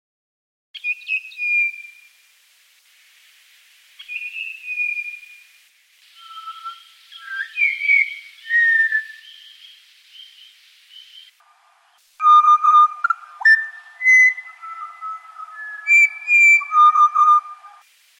Pied Butcherbird
Cracticus nigrogularis
Songs & Calls
The Pied Butcherbird’s voice is a beautiful, melodious fluting, sometimes given in turn by several individuals.
One of the species’ readily recognised aspects is its beautiful, haunting, flute-like song which may be given throughout the day and sometimes well into moonlit nights.
pied-butcher-bird-cracticus-nigrogularis.mp3